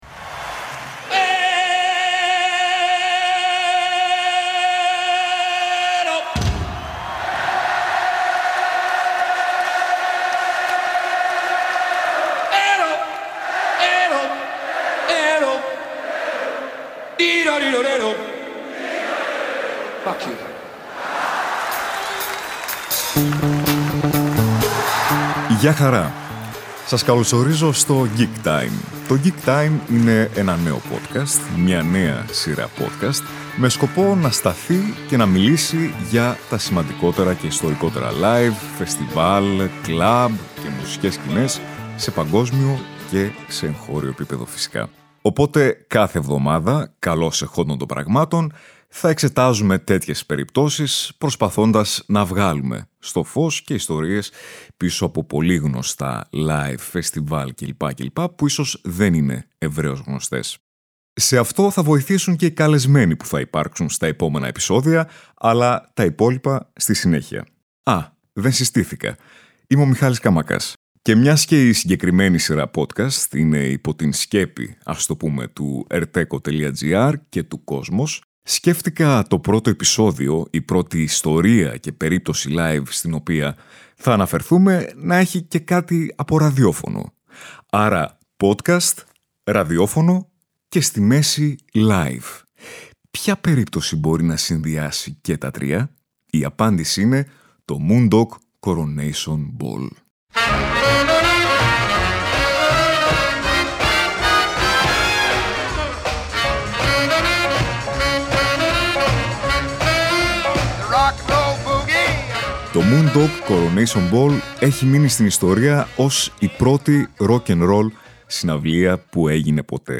Bonus, η συμμετοχή ονομάτων από τον χώρο της μουσικής που θυμούνται και δίνουν την προσωπική τους μαρτυρία από τις πιο δυνατές gig εμπειρίες τους.